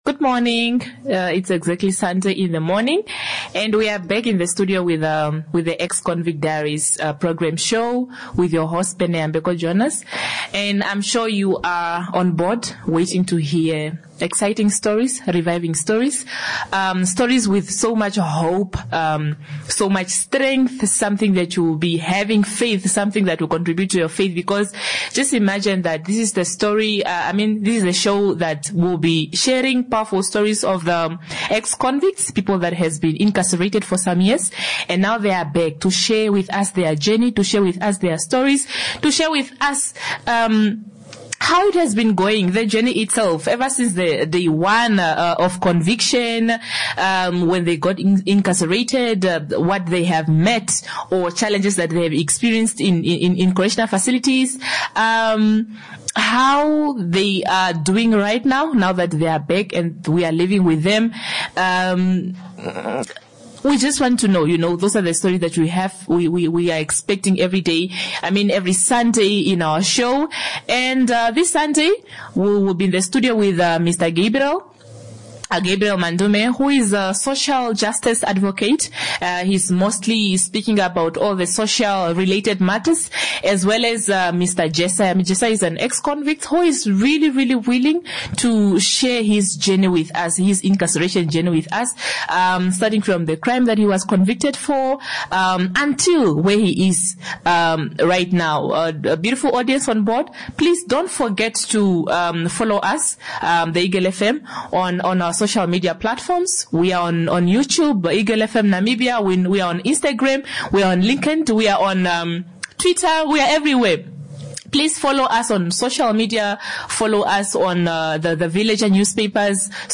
This Sunday, a social justice advocate and an ex-convict share insights on various matters, including an incarceration journey that challenges stigma, inspires hope, and demands change.